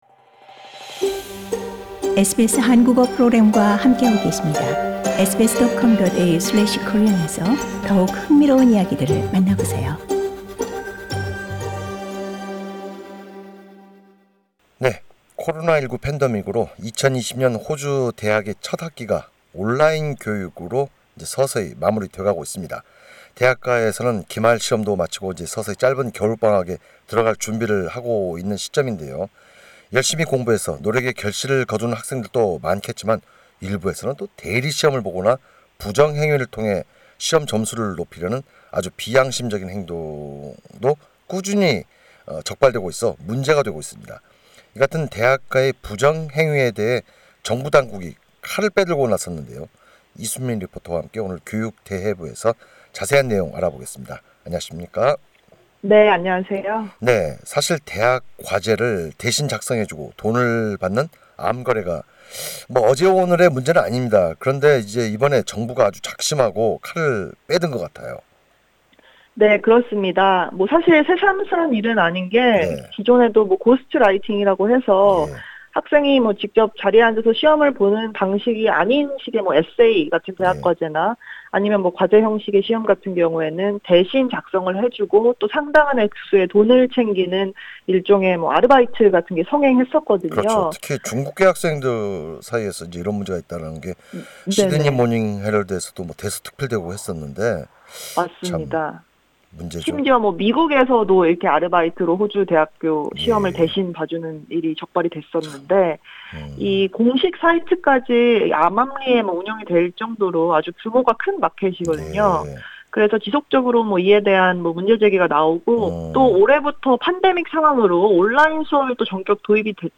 SBS 한국어 프로그램